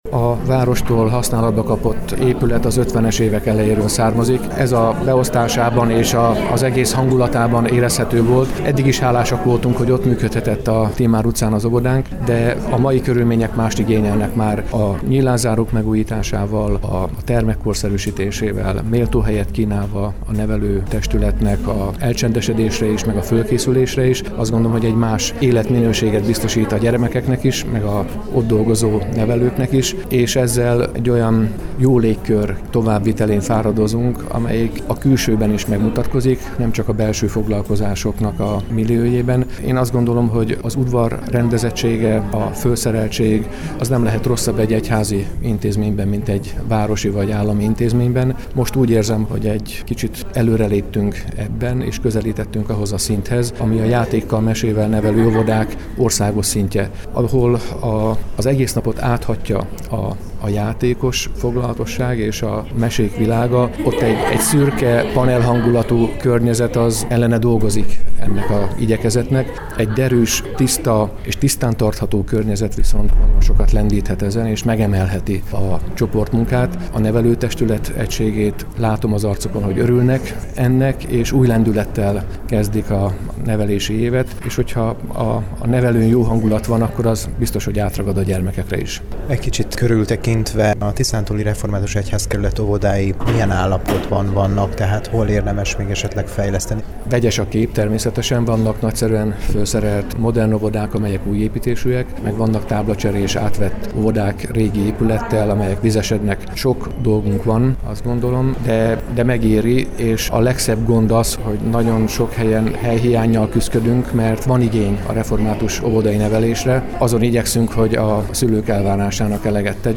Hálaadó istentisztelet a Debrecen-Kossuth utcai Református Templomban - hanganyaggal